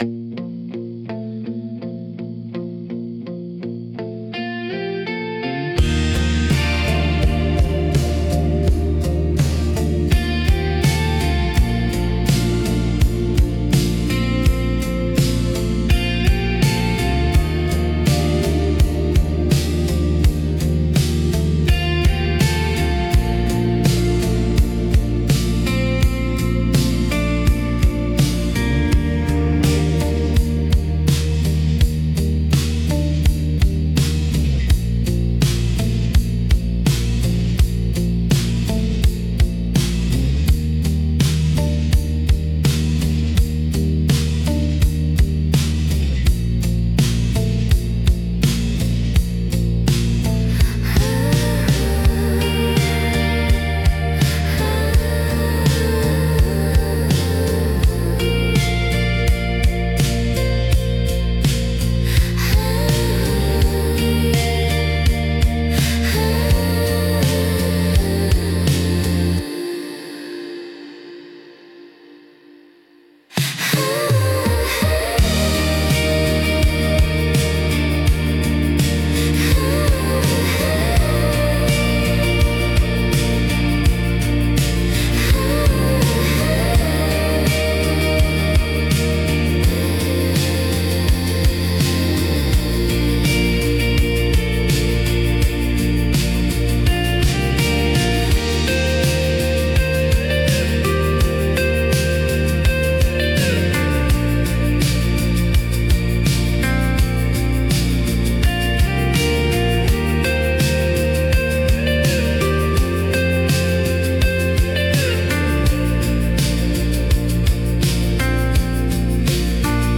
BGMセミオーダーシステムドリームポップは、繊細で幻想的なサウンドが特徴のジャンルです。
静かで美しい音の重なりが心地よく、感性を刺激しながらも邪魔にならない背景音楽として活用されます。